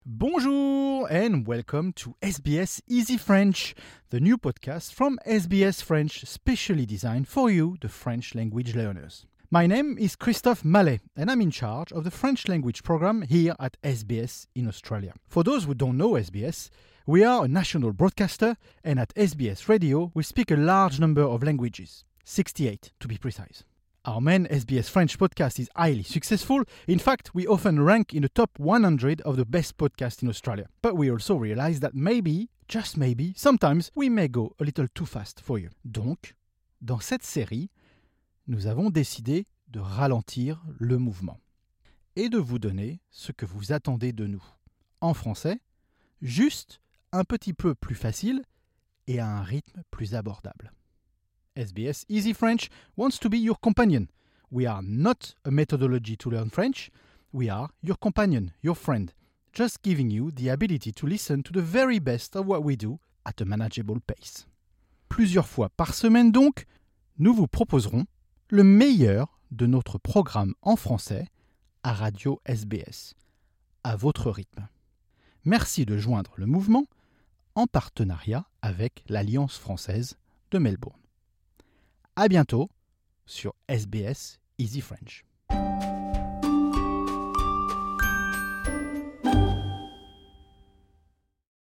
SBS Easy French wants to be your companion, we are NOT a method to learn French, we are your companion, just giving the ability to listen to the very best of what we do… at a manageable pace..